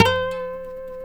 C#5 HAMRNYL.wav